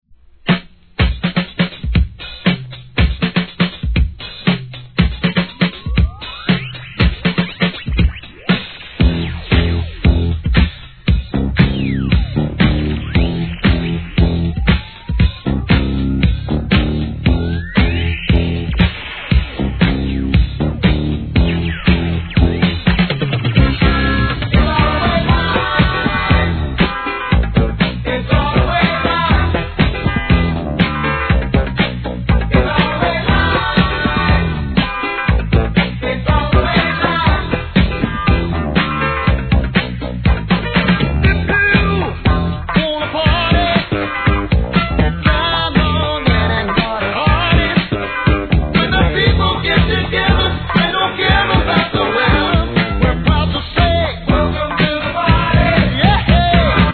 ¥ 770 税込 関連カテゴリ SOUL/FUNK/etc...